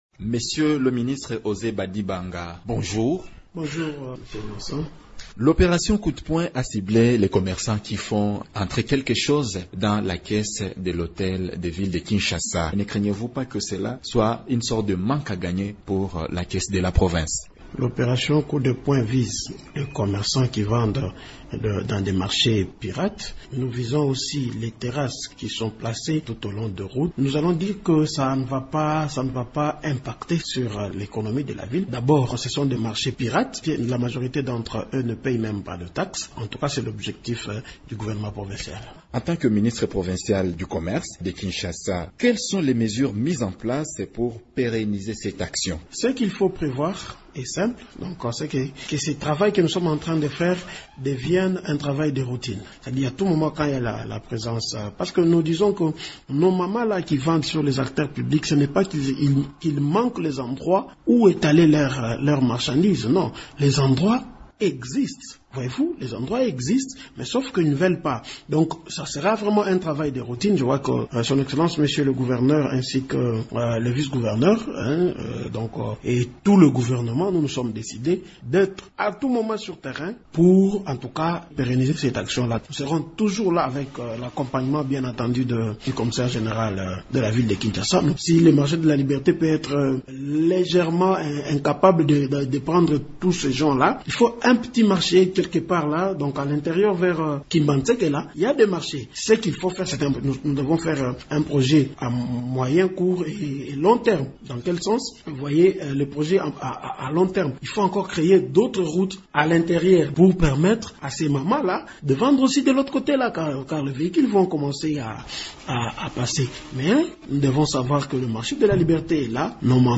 Invité de Radio Okapi ce samedi 14 janvier, le ministre provincial du Commerce de Kinshasa, Osé Badibanga, a précisé qu’étant donné que la majorité de commerçants qui vendent sur les grandes artères de la ville de Kinshasa ne payent pas des taxes, cette opération n’aura pas un impact négatif sur la caisse de l'Hôtel de ville de Kinshasa.